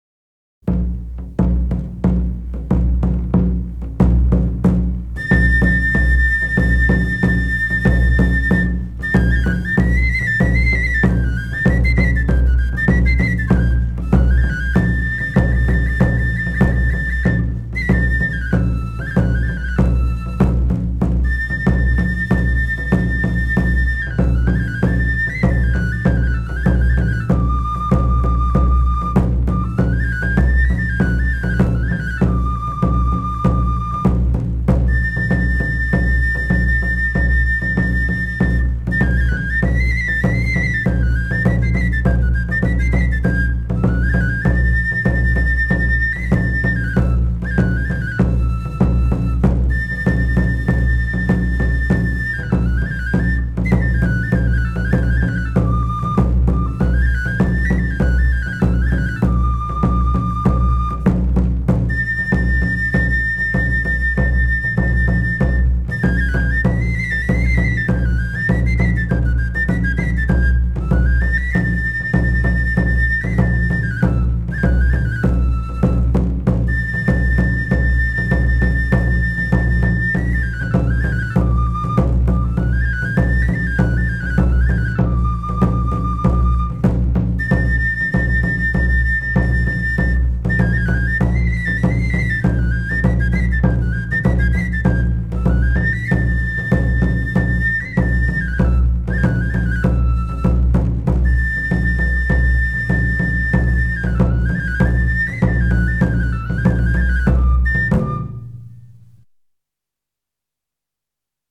• Flauta rociera i tamborcil (Andalusia)
04-flauta-rociera-y-tamborcil-andalusia.m4a